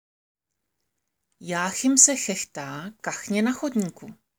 Tady si můžete stáhnout nahrávku na výslovnost CH – Jáchym se chechtá